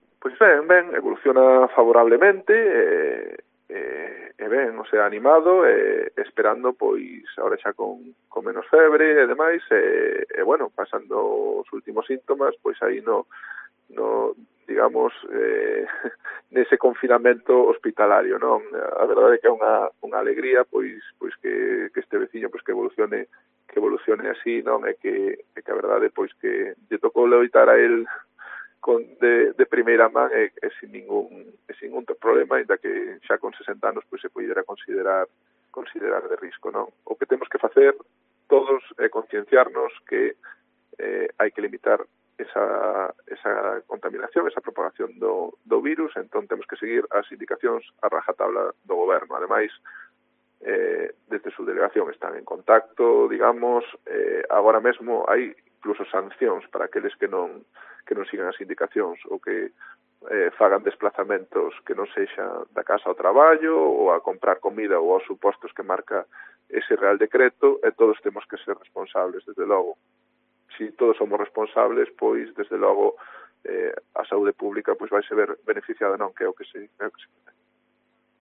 DECLARACIONES de Fran Cajoto, alcalde de Foz